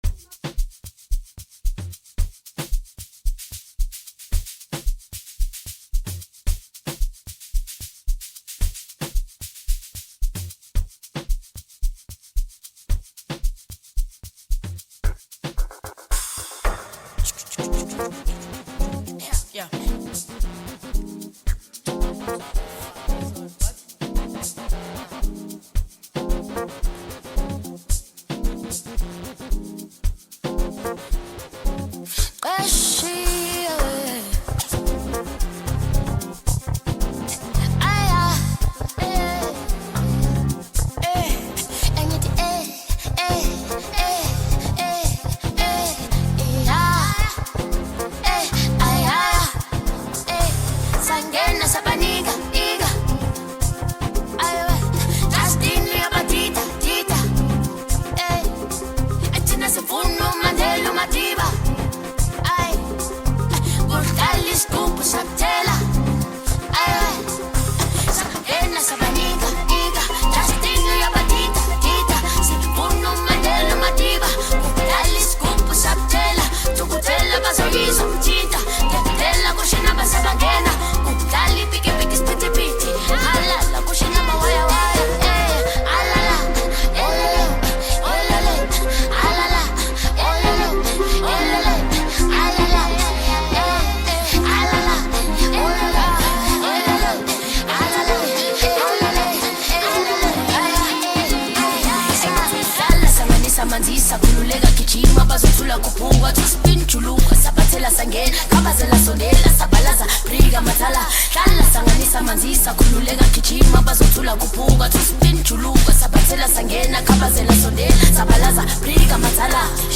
blends Afrobeat with contemporary sounds
With its high energy tempo and catchy sounds